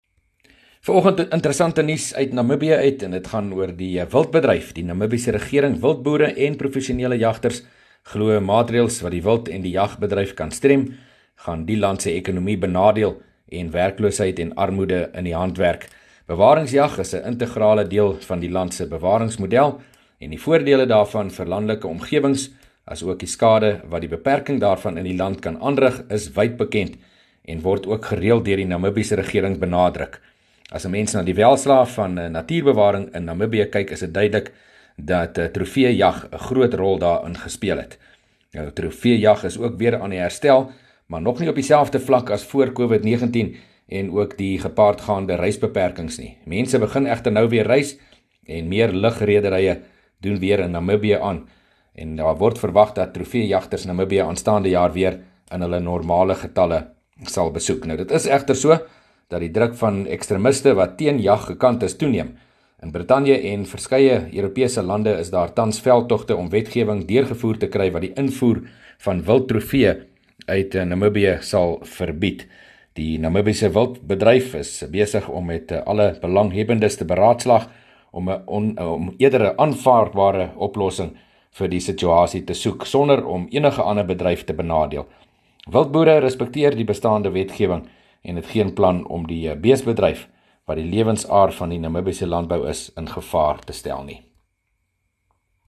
14 Nov PM berig oor nuus van trofeejag in Namibië